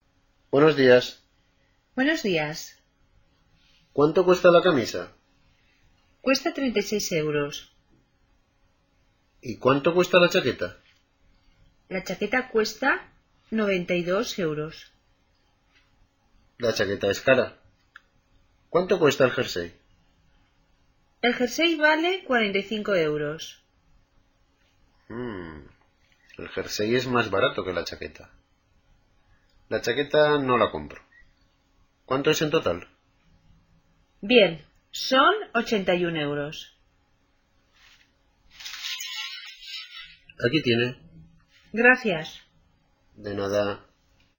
Listen to a conversation between a shop assistant and a customer and complete the text.